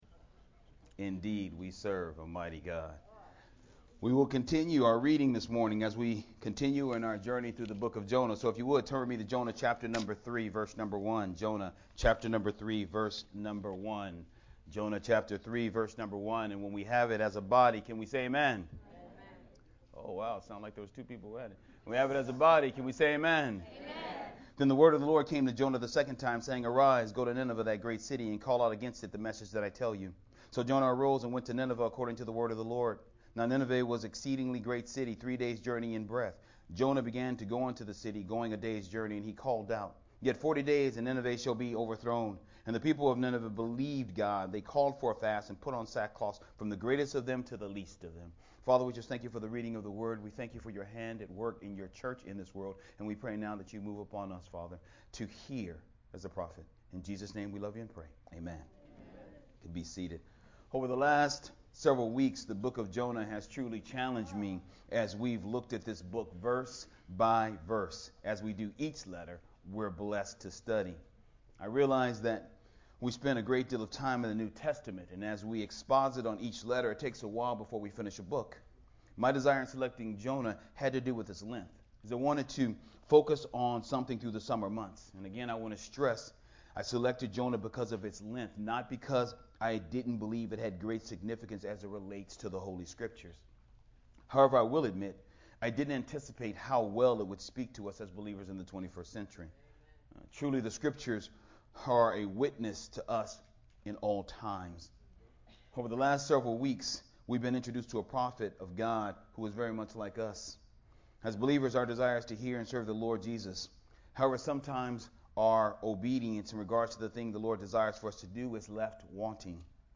Sermon From Jonah 3:1-4